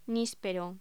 Locución: Níspero
voz